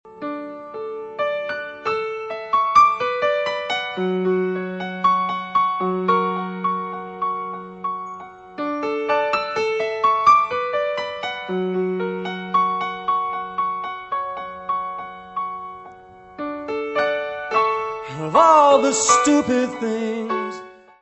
piano
baixo
bateria.
Music Category/Genre:  Pop / Rock